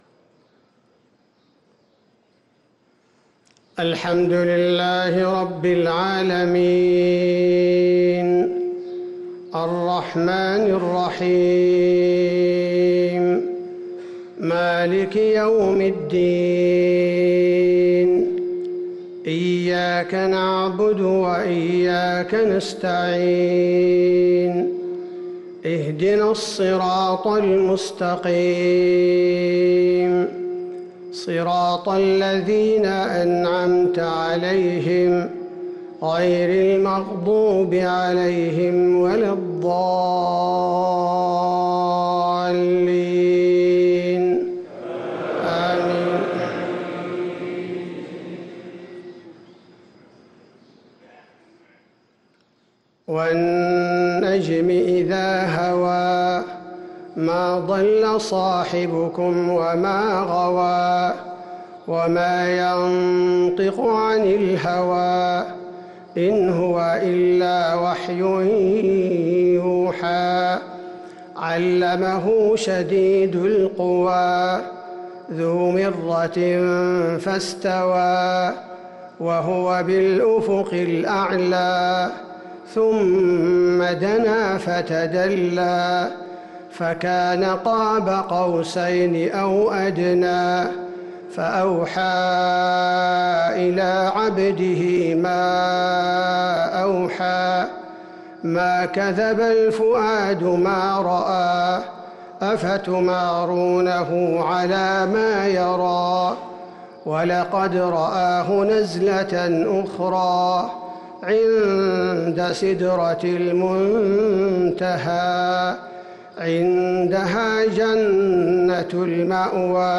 صلاة الفجر للقارئ عبدالباري الثبيتي 20 رمضان 1444 هـ
تِلَاوَات الْحَرَمَيْن .